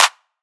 MURDA_CLAP_BAGUETTE.wav